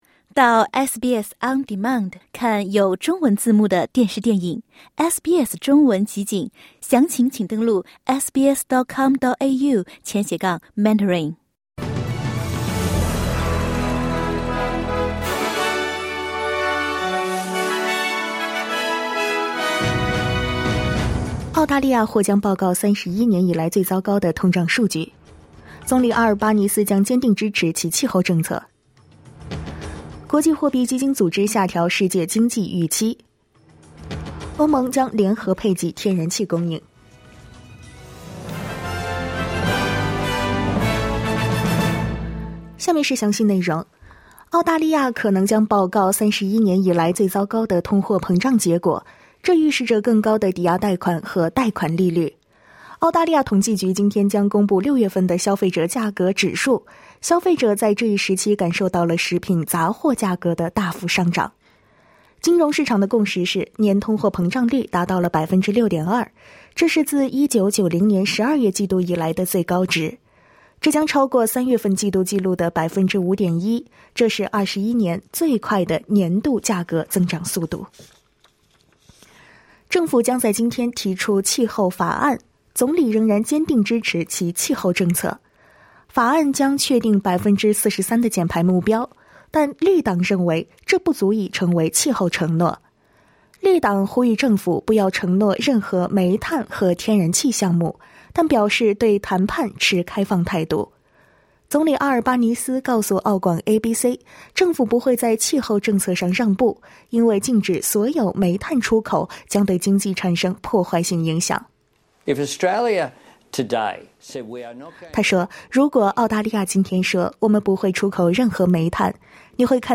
请点击收听SBS普通话为您带来的最新新闻内容。